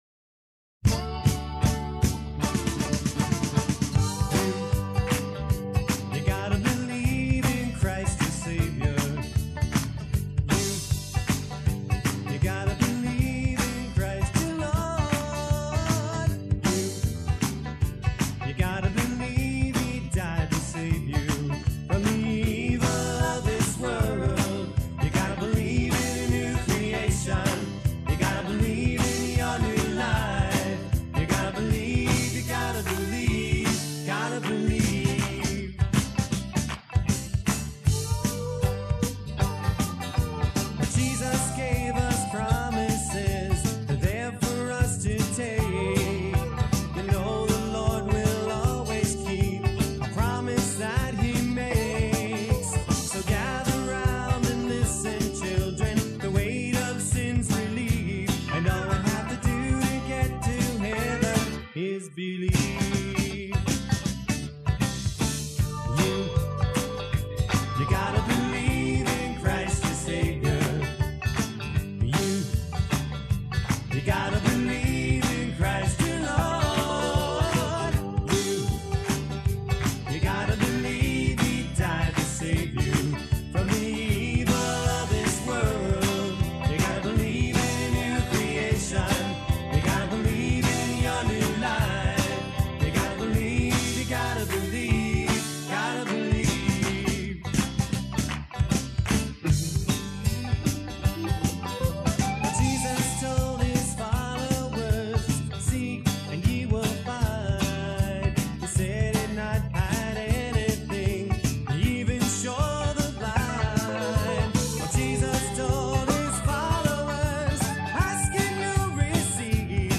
Synthesizer on all selections
Bass guitar on all selections
String and synthesizer arrangements on all selections
Drums and percussion on all selections